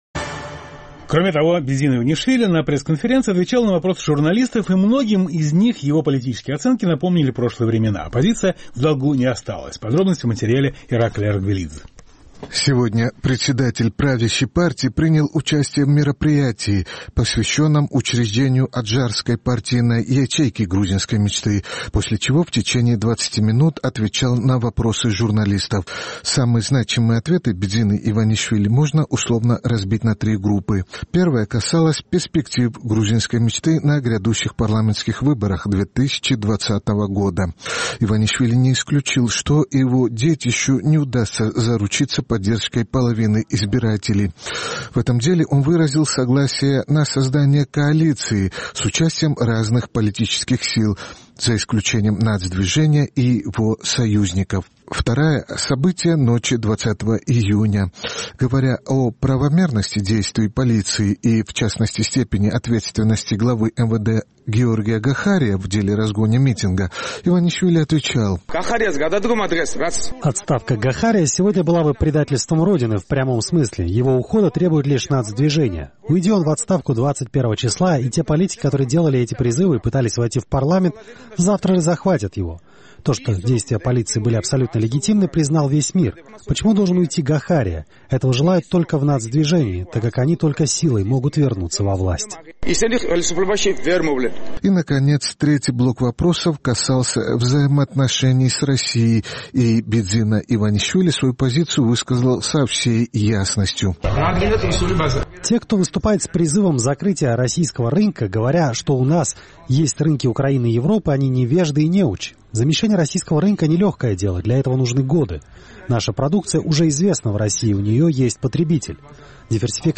Сегодня Бидзина Иванишвили на импровизированной пресс-конференции отвечал на вопросы журналистов, и многим из них его политические оценки напомнили прошлые времена.